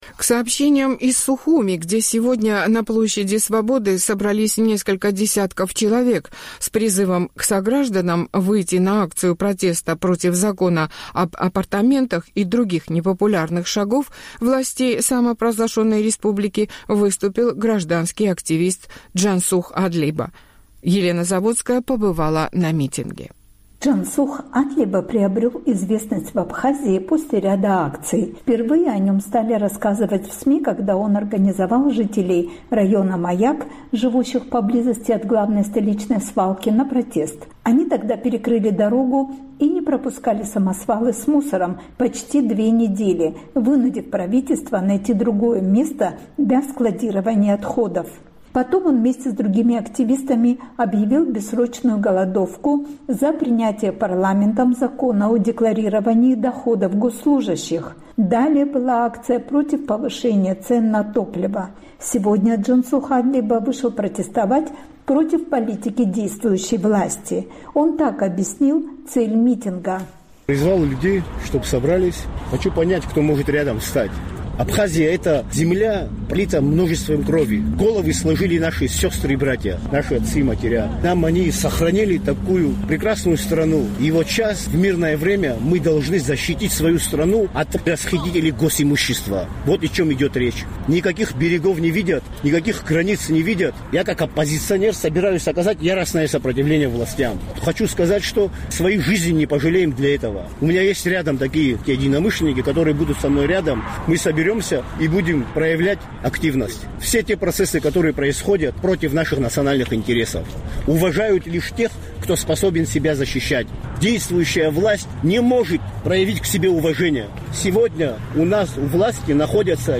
Сегодня в Сухуме на площади Свободы собрались несколько десятков человек.